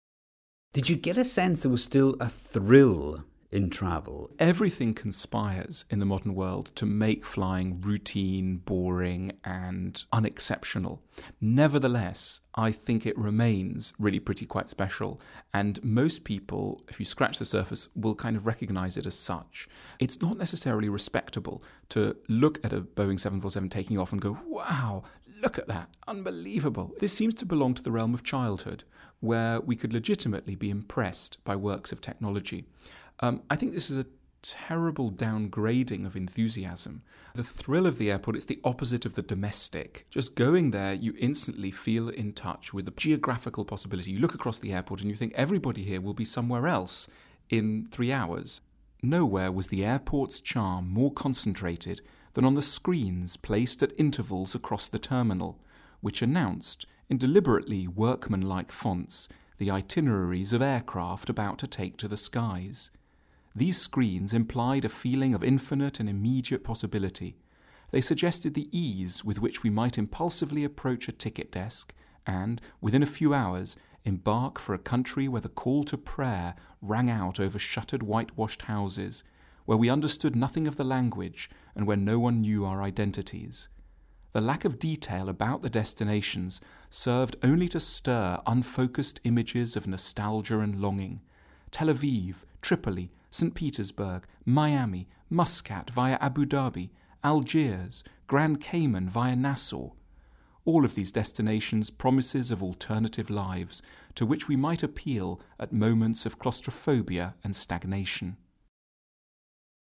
Switzerland-born Englishman and philosopher Alain de Botton speaks about his time at London Heathrow writing a book about this “non-place”.